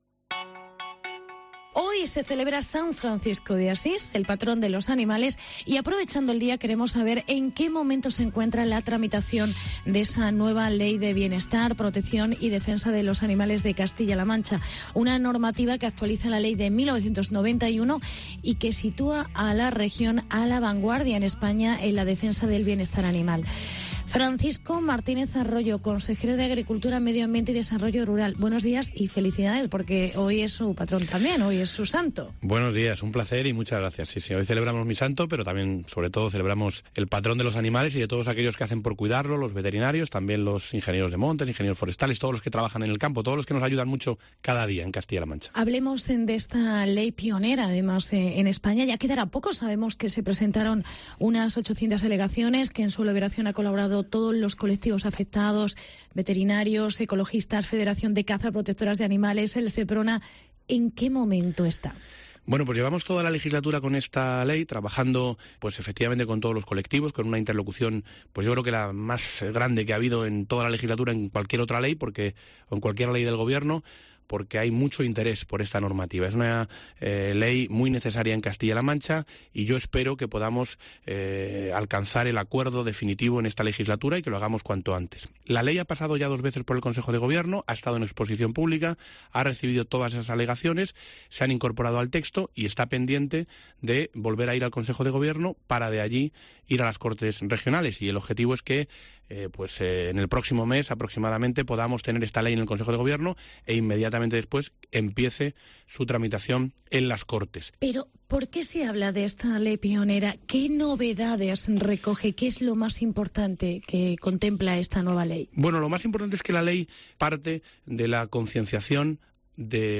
Siguen los pasos para otra ley "pionera": La Ley de Bienestar Animal. Entrevista Consejero Francisco Arroyo